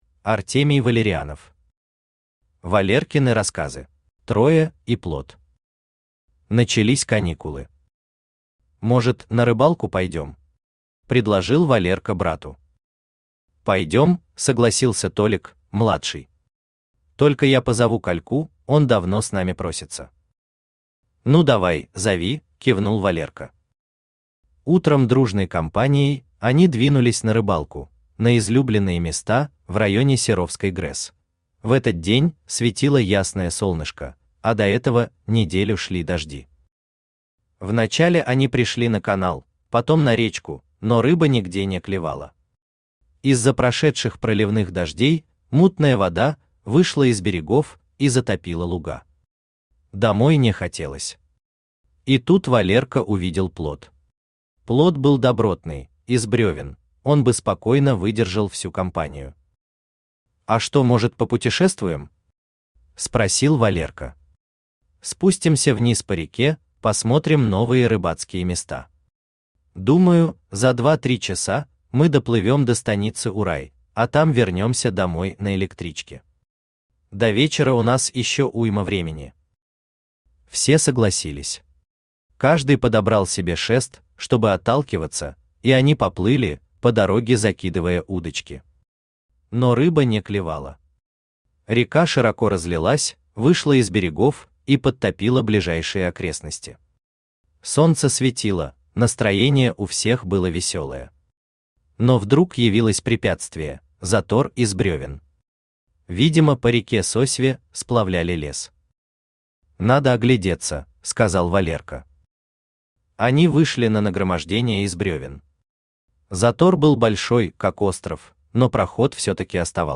Аудиокнига Валеркины рассказы | Библиотека аудиокниг
Aудиокнига Валеркины рассказы Автор Артемий Валерианов Читает аудиокнигу Авточтец ЛитРес.